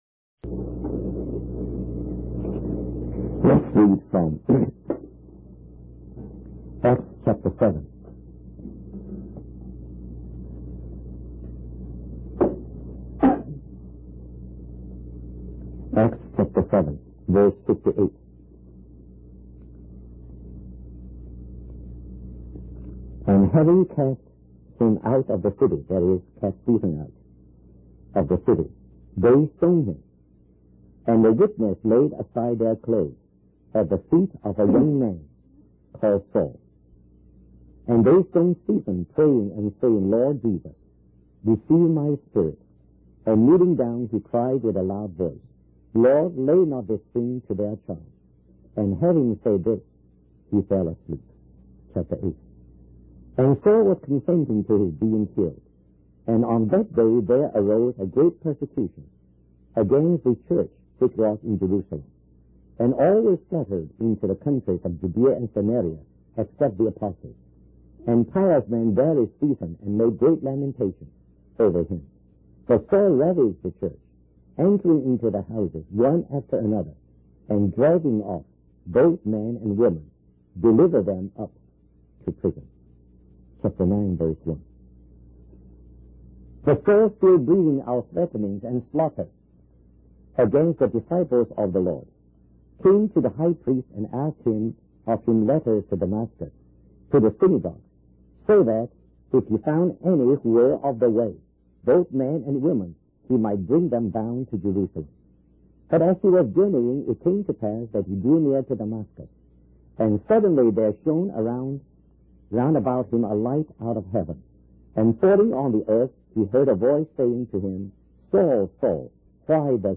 In this sermon, the speaker emphasizes the importance of learning practical lessons before trying to fulfill a vision from God. He uses the example of Saul, who became the apostle Paul, to illustrate this point.